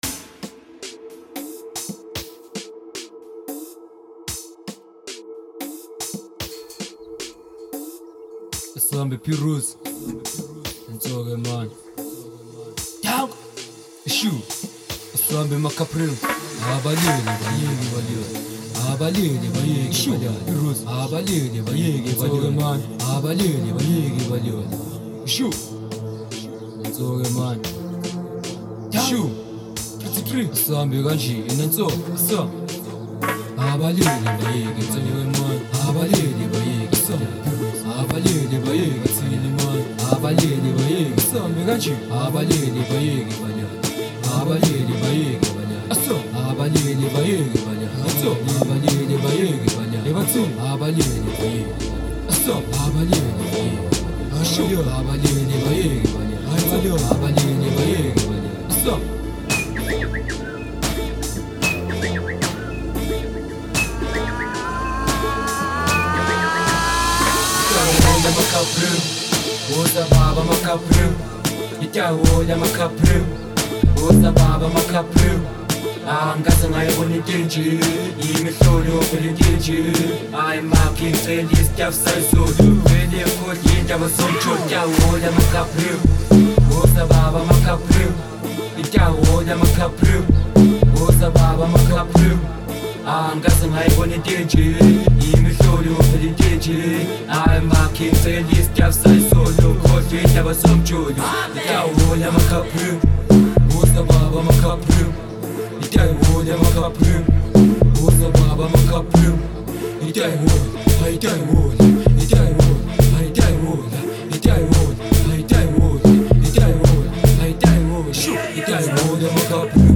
05:21 Genre : Amapiano Size